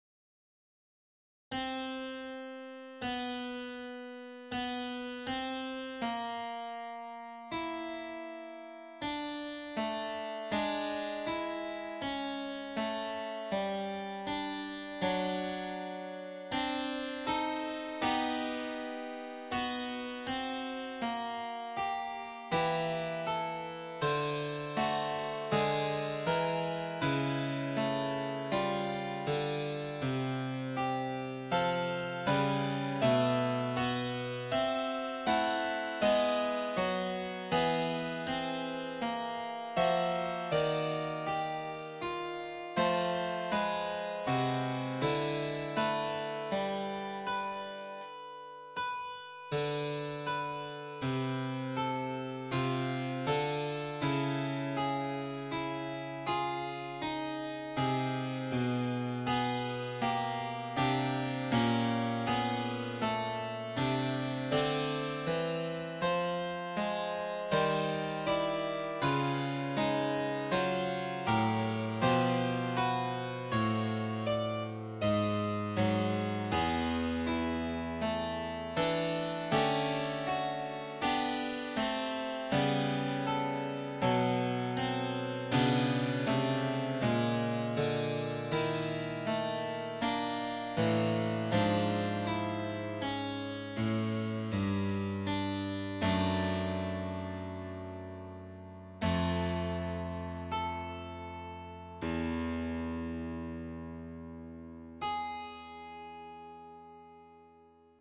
a site of fugues
the main figure here is the rising m2 followed by the falling M2 (pay no attention to the answer).
this lends itself to two patterns: the octatonic scale (see the soprano mm. 13-16) and phrygian cadences (the one at the end is the inarguable, but the bass egressing down by a half-step evokes it, even if the voices above are a mess).
for 4 voices